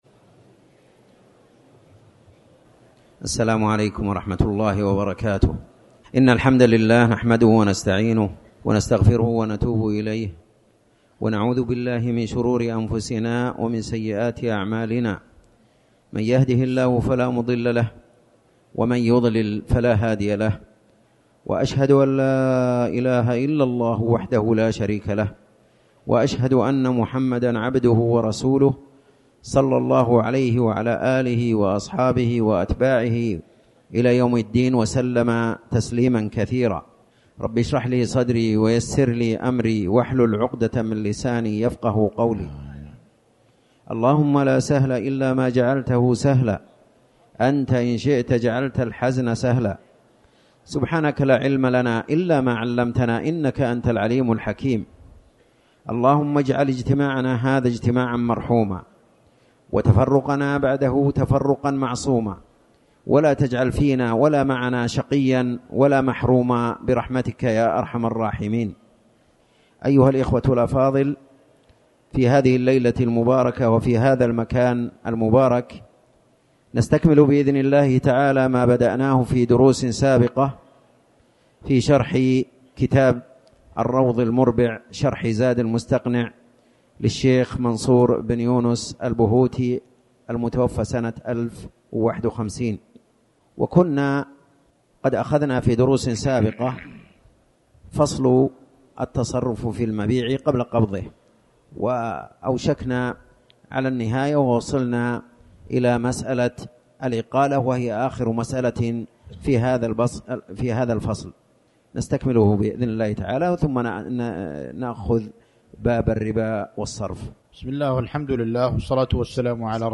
تاريخ النشر ٢٦ ربيع الأول ١٤٤٠ هـ المكان: المسجد الحرام الشيخ